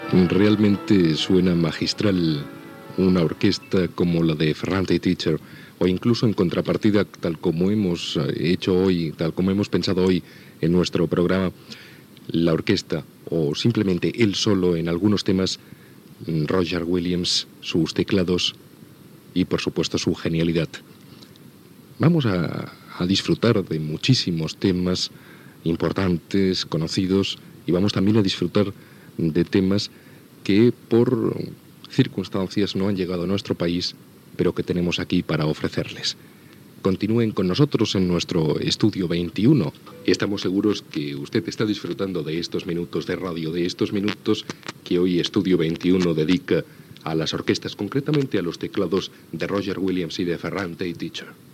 Identificació del programa i presentació d'un tema musical.
Comait del programa i careta de sortida.
Musical
FM